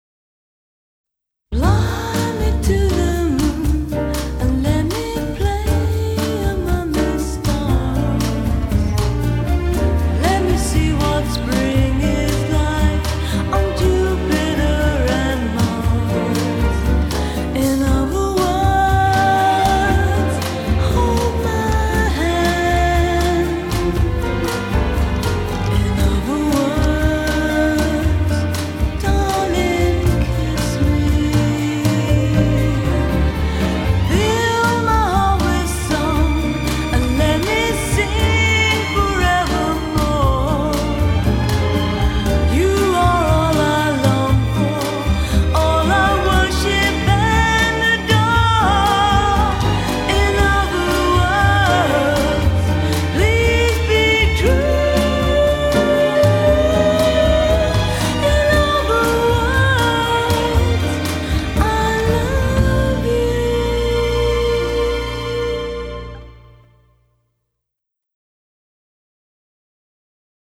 легендарная боссанова
первоначальная версия в титрах эндинга